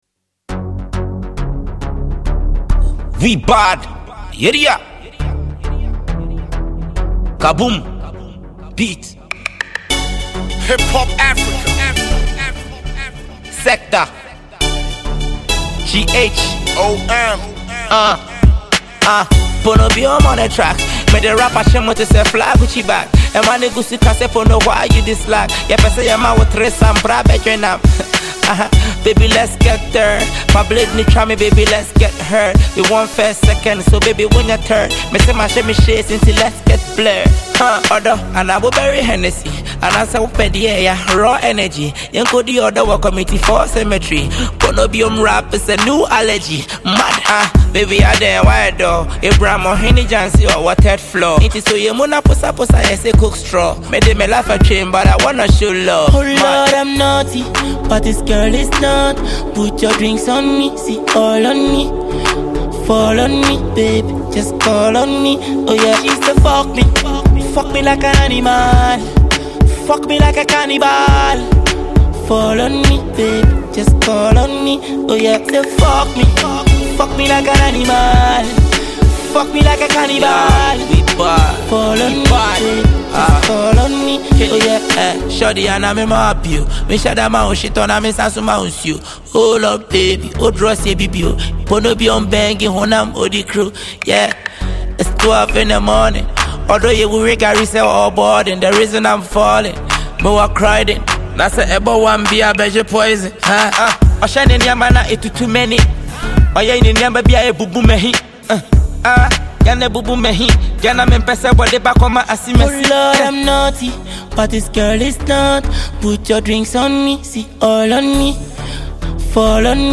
Hip-Hop
Ghanaian MC